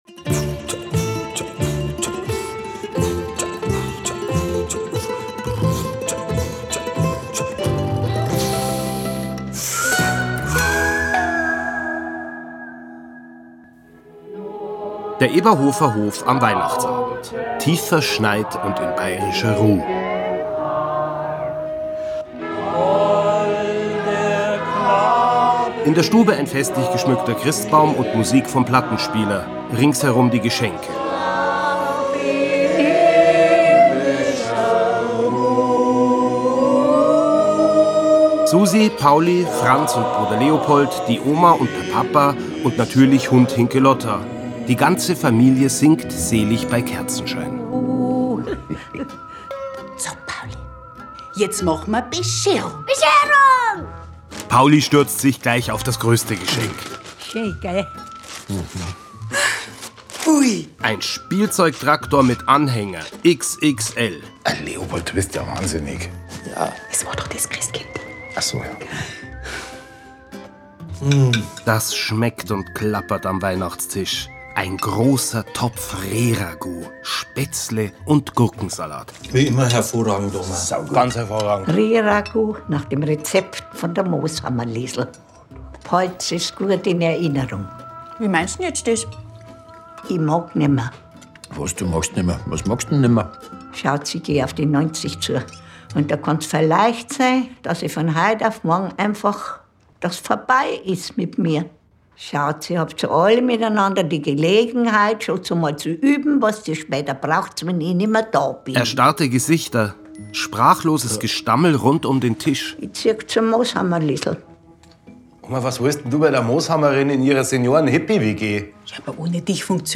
Filmhörspiel mit Sebastian Bezzel, Simon Schwarz, Lisa Maria Potthoff u.v.a. (2 CDs)
Sebastian Bezzel, Simon Schwarz, Lisa Maria Potthoff (Sprecher)